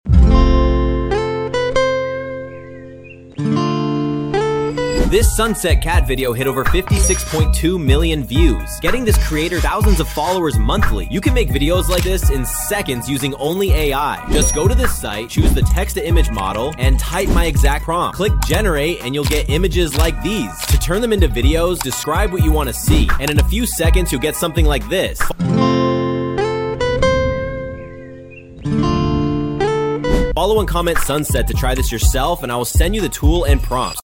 This sunset cat playing guitar sound effects free download